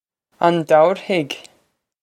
Pronunciation for how to say
On dow-er-hig?
This is an approximate phonetic pronunciation of the phrase.